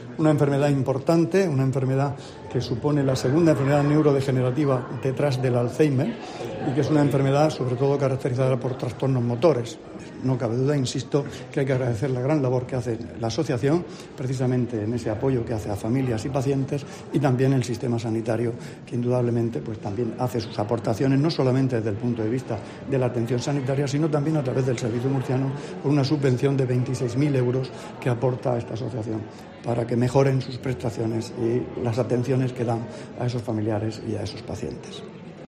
Juan José Pedreño, consejero de Salud